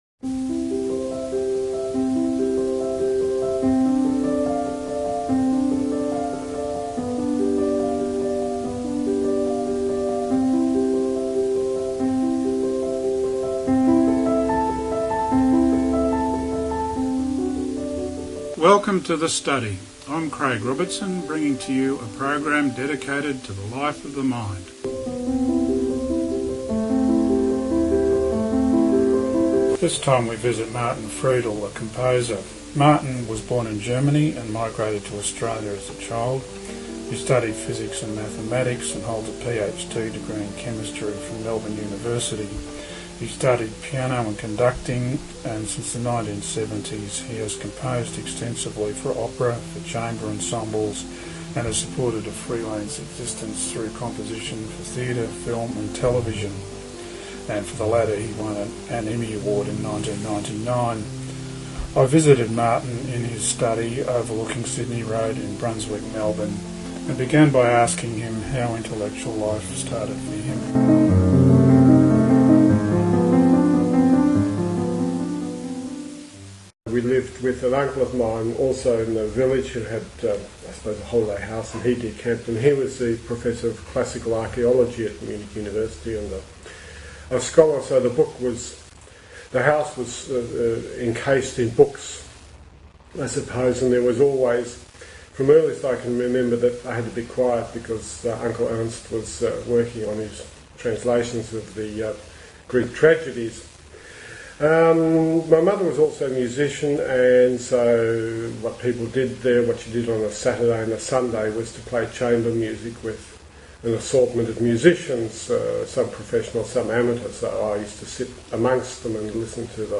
The Study: Podcast No.1 - Interview
This podcast also includes my two regular themes: Introduction and final announcements: Prelude from J.S. Bach: Prelude and Fugue in C, Book 1, BWV846